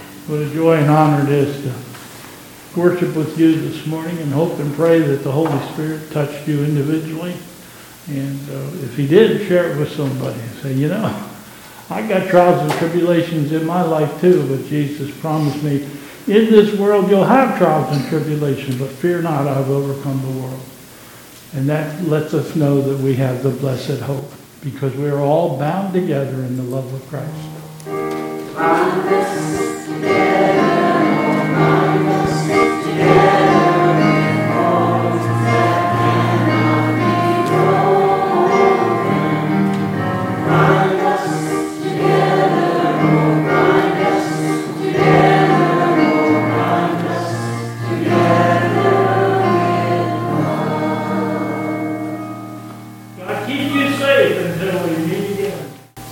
Closing Chorus: "Bind Us Together"